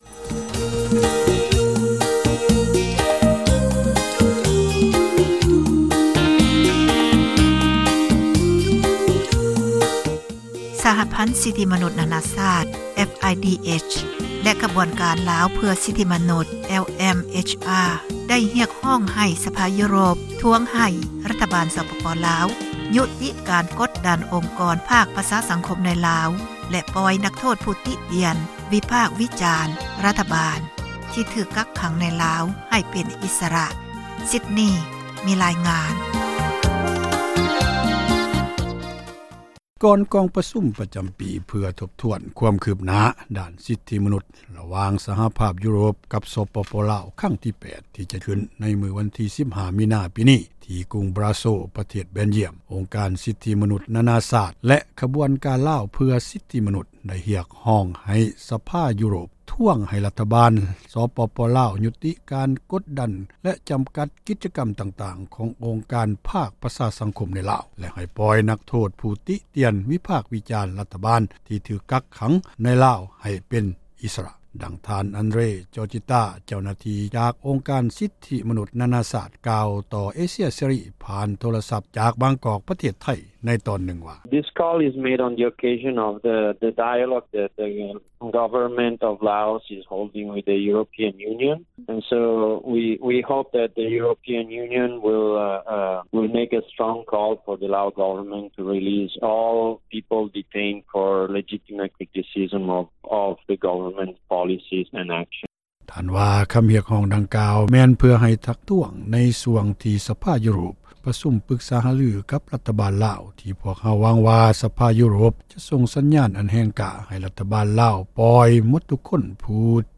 ກ່າວຕໍ່ RFA ຜ່ານທາງໂທຣະສັບ ຈາກບາງກອກ ປະເທດໄທ